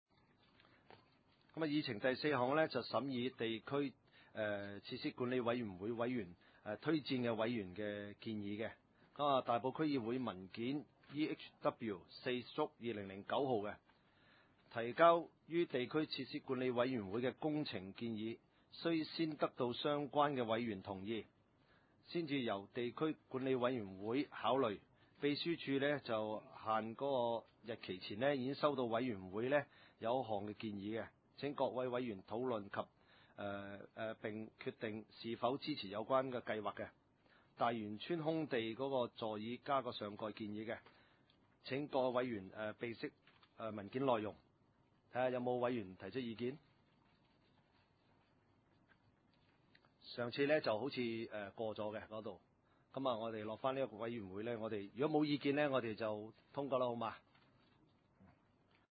地點：大埔區議會秘書處會議室